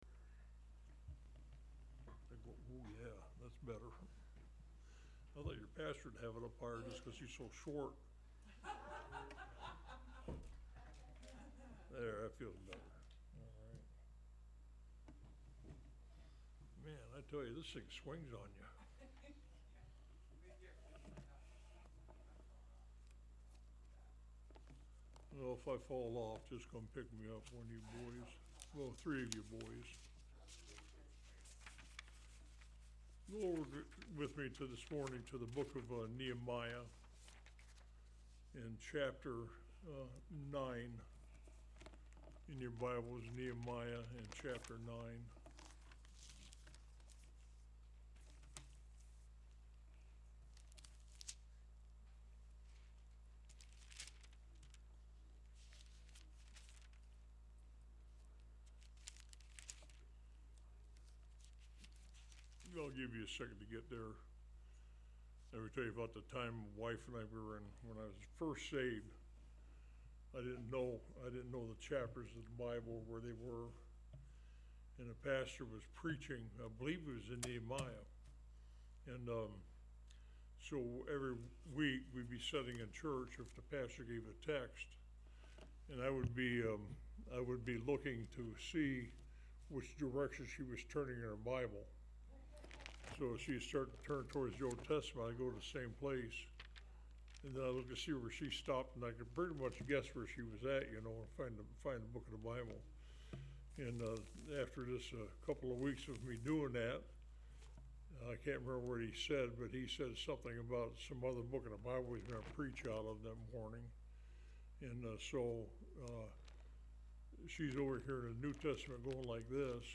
Online Sermons – Walker Baptist Church